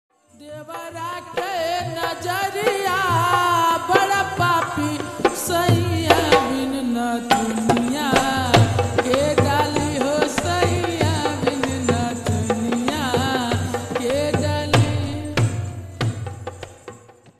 Bhojpuri Song
(Slowed + Reverb)